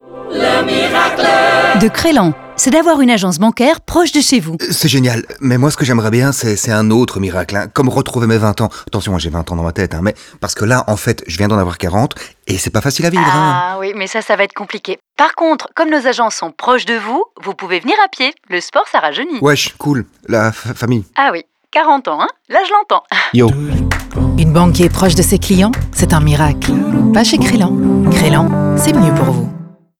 Chaque spot donne la parole à une personne stupéfaite par le miracle, qui cherche aussitôt à le partager avec une autre personne dans le spot suivant. Après une semaine, deux spots plus classiques de 30 secondes prennent le relais.